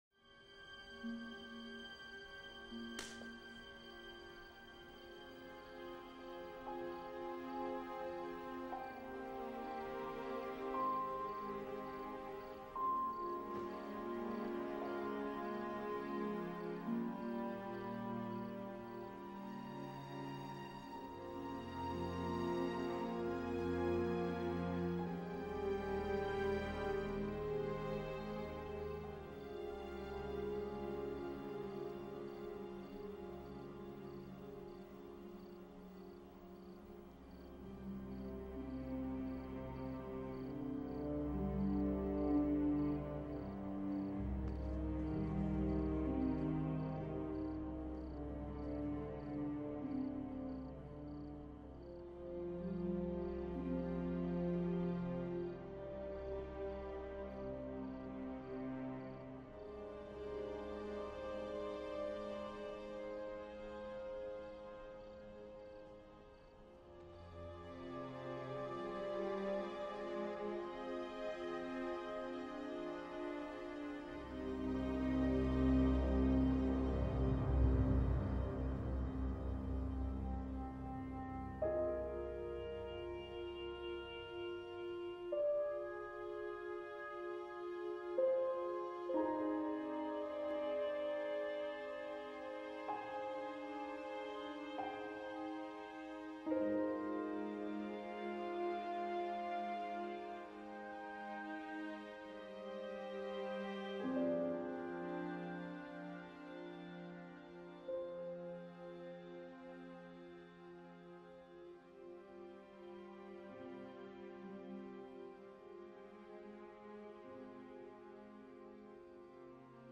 Musique diffusée: Lors du dénouement de l’épisode.
Musique issue de l’album: DVD rip
Original Score